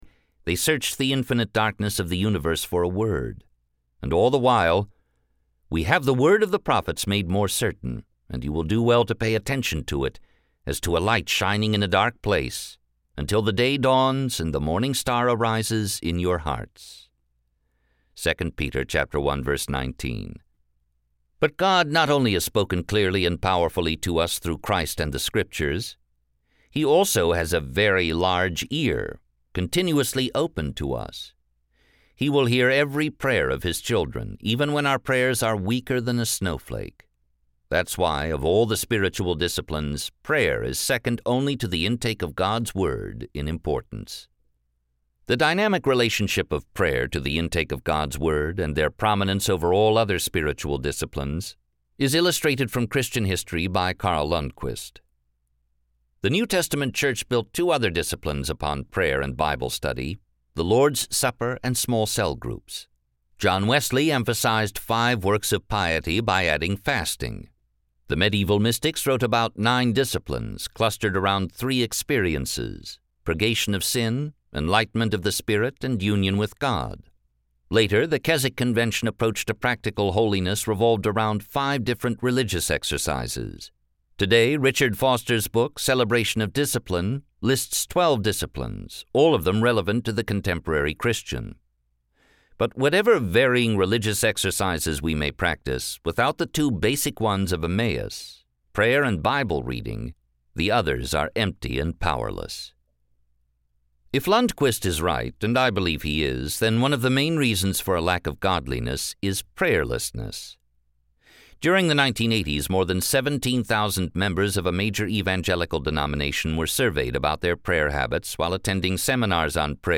Spiritual Disciplines for the Christian Life Audiobook